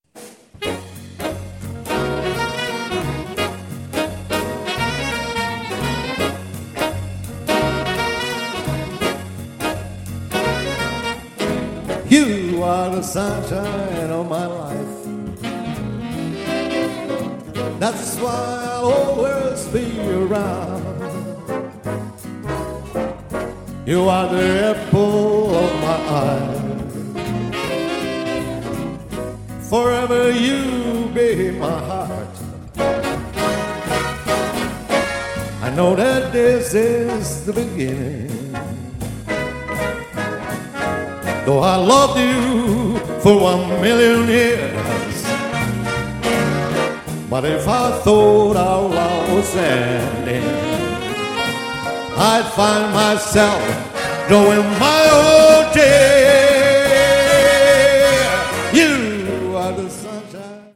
Le registrazioni sono tutte dal vivo
ottima la prova di swing dell'orchestra